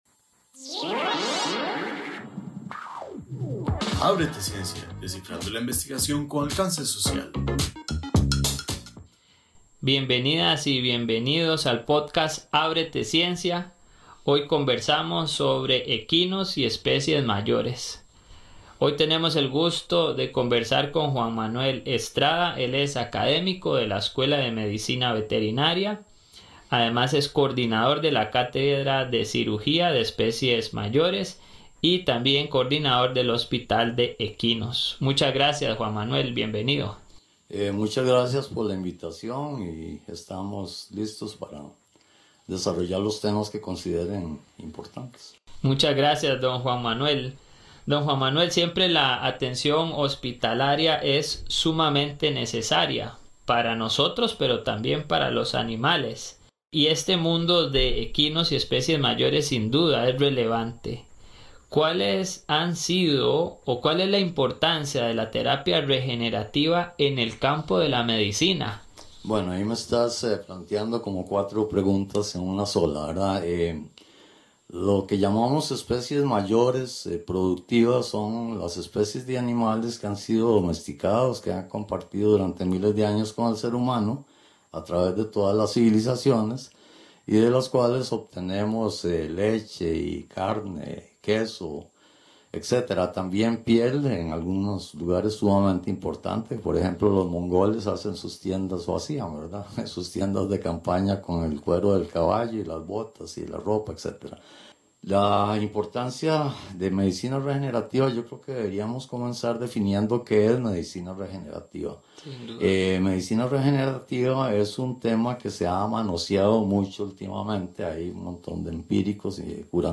¡Bienvenidos al podcast ábrete ciencia! Aquí encontrarás conversaciones fascinantes sobre temas científicos de gran relevancia social.